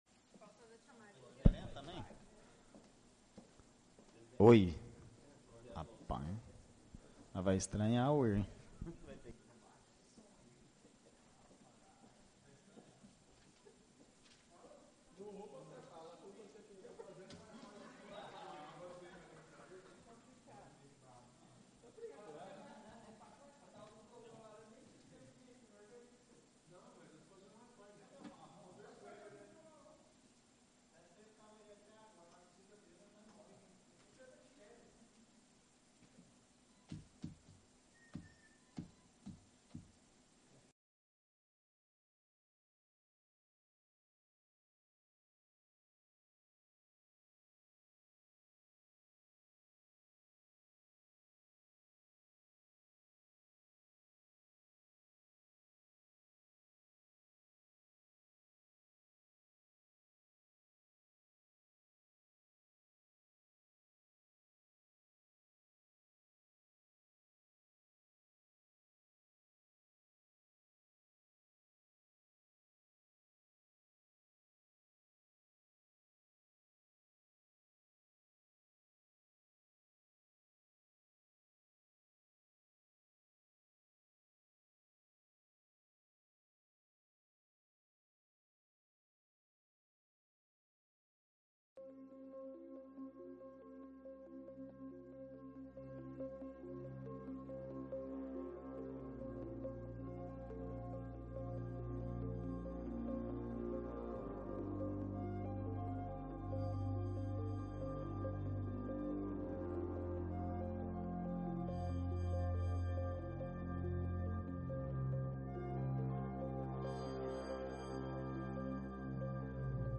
Áudio da 19.ª reunião ordinária de 2021, realizada no dia 22 de Novembro de 2021, na sala de sessões da Câmara Municipal de Carneirinho, Estado de Minas Gerais.